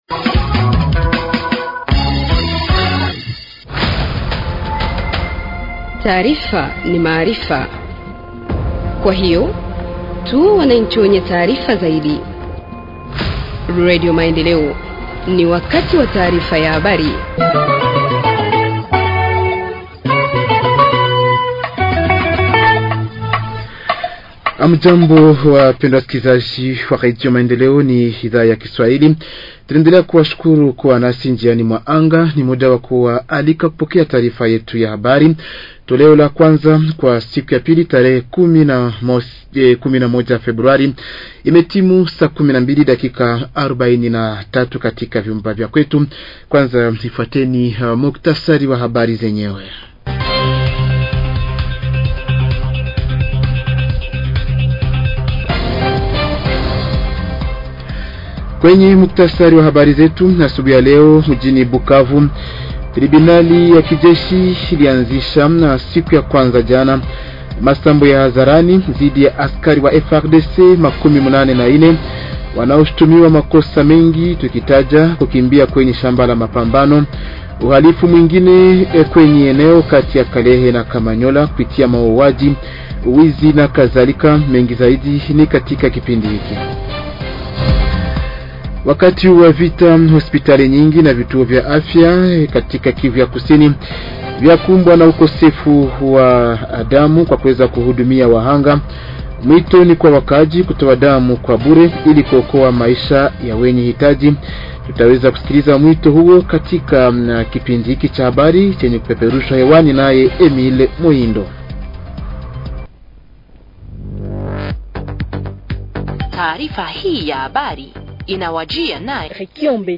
Journal en Swahili du 11 février 2025 – Radio Maendeleo